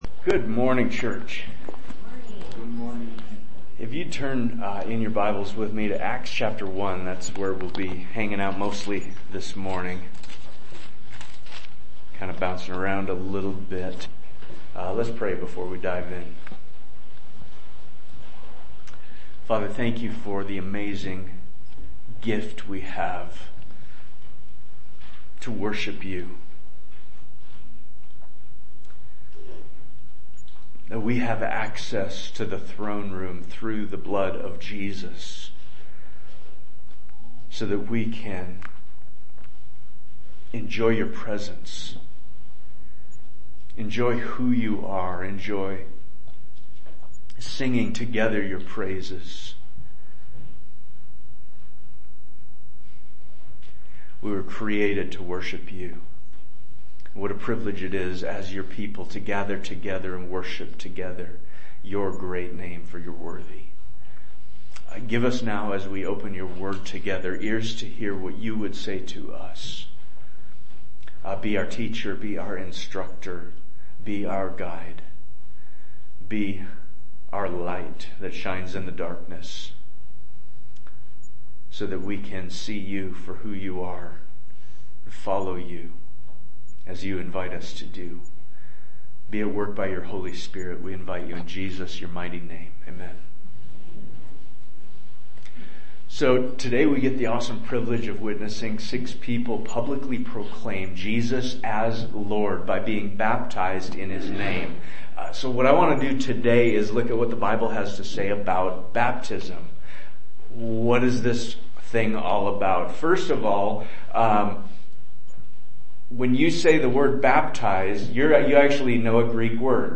Sermon Manuscript